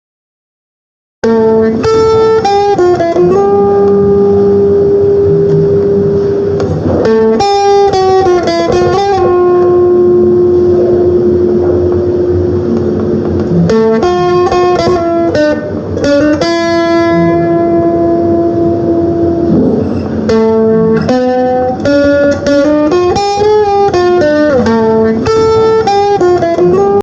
Простите, очень узнаваемая мелодия, но в жутчайшей обработке!